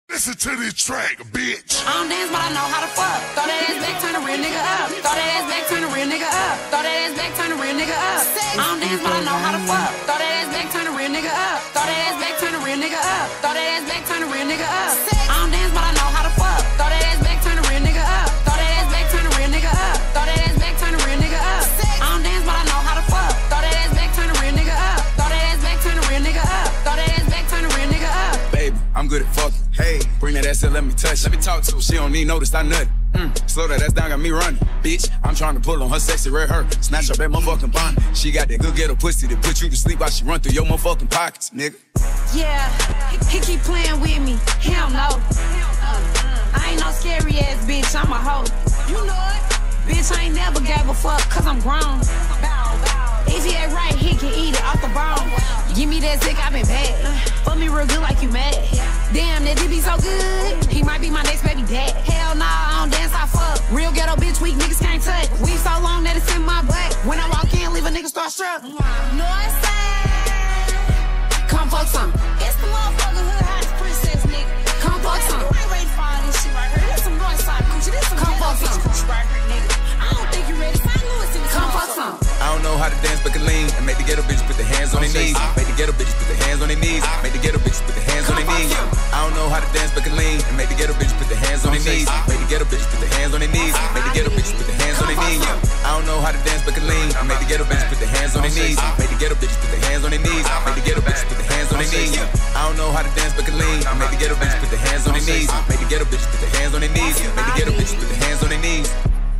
• Качество: 320 kbps, Stereo
Mashup